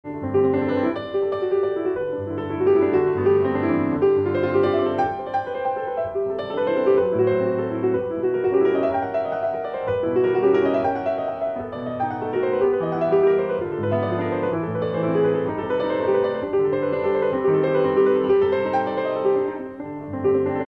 鋼琴